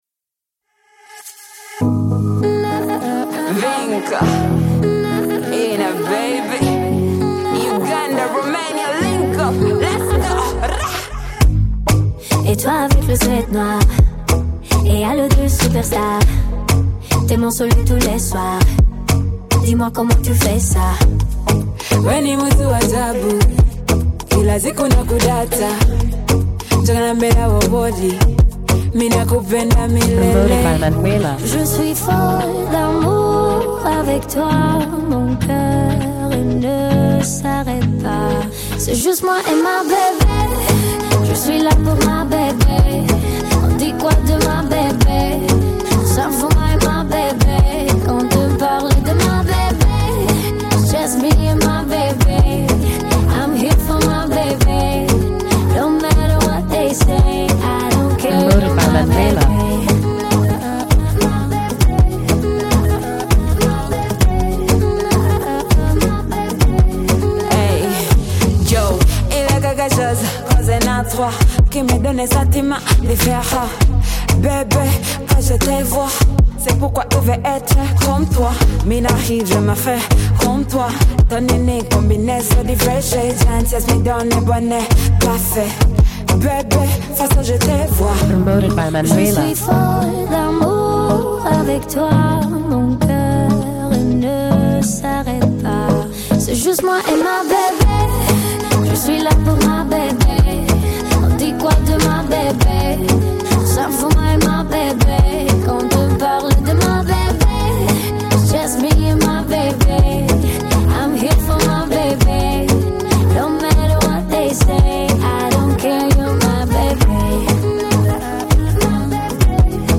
Radio Edit
La chanteuse roumaine